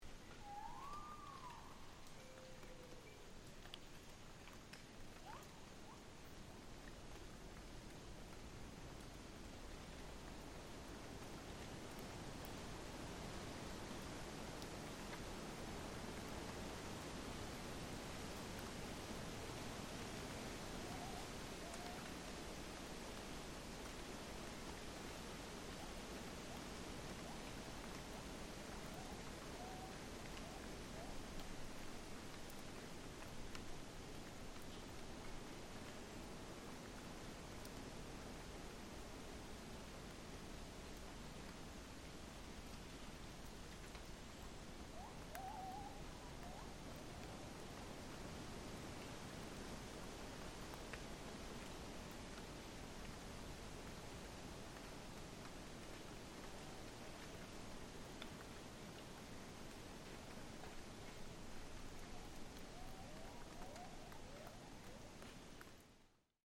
The jungle settles after a strong rain - light drops of rain patter on leaves while wind swells through the leaves. Distant gibbons sing from deep in the mountains.